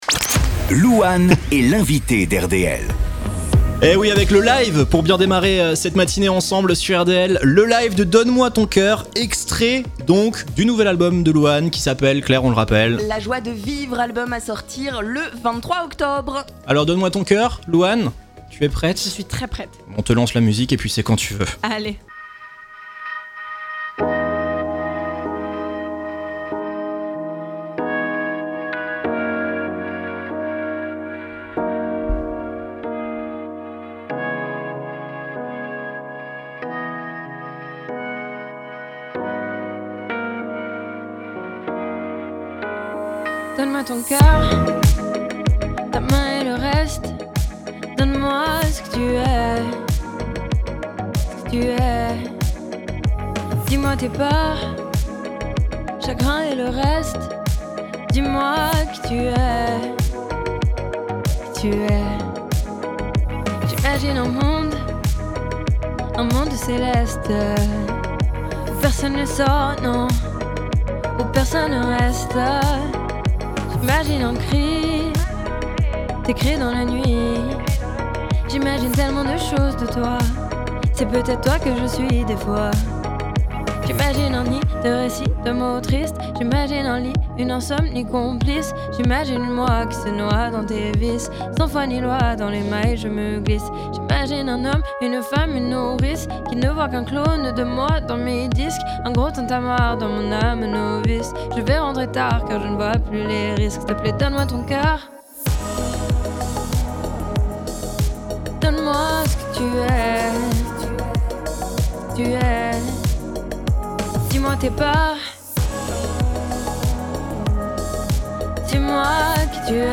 A l'occasion de la sortie de son nouvel album, réécoutez le live de LOUANE sur RDL !
interview